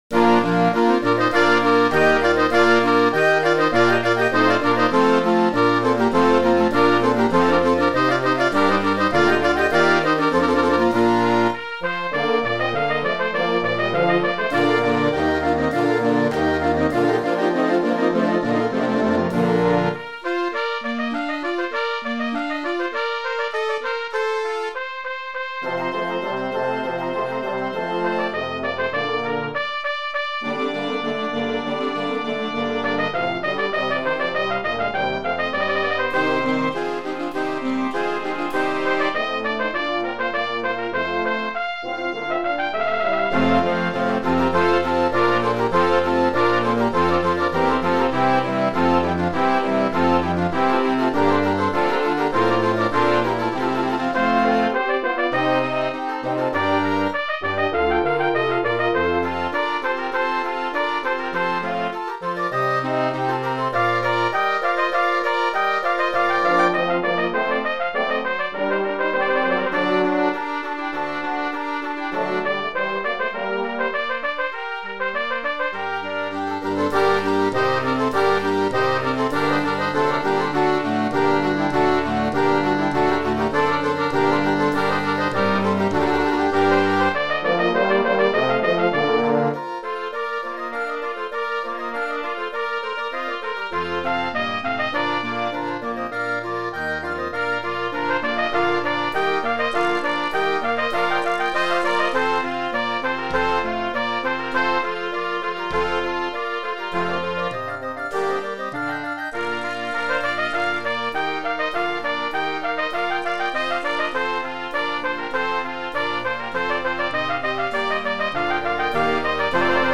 Voicing: Trumpet and Concert Band